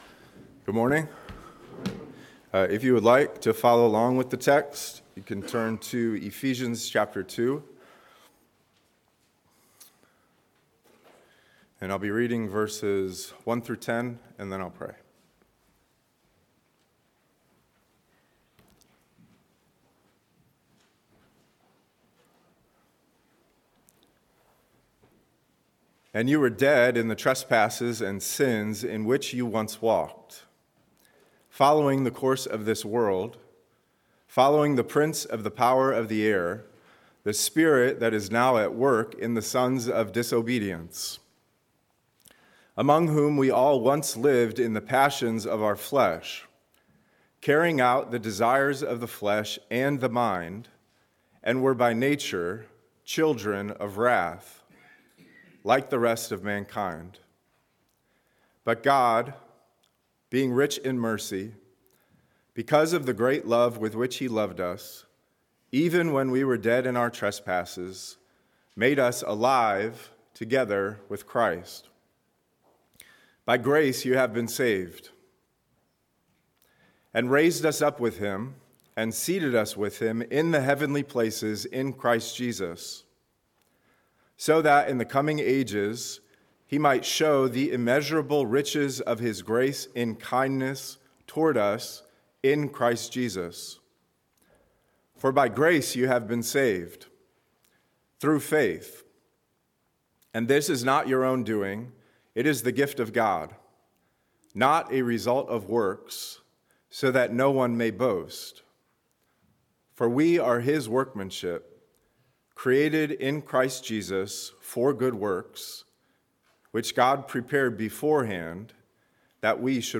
A message from the series "Ephesians 2025."